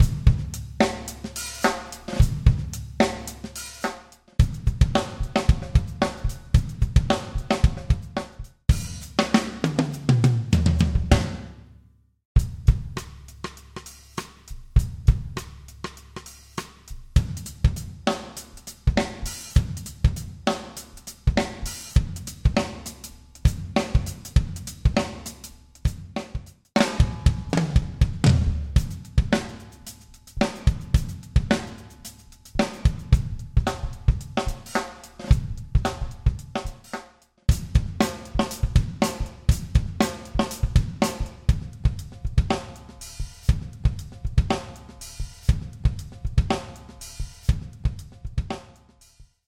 Get the ideal collection of live acoustic drum loops for Pop, Funk and Rock music production.
Download live acoustic Pop drum loops for pop, rock and indie music production.
Download Loops and Samples 70-160 Bpm
modern_funk_drums_v1.mp3